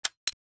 rifle_empty.wav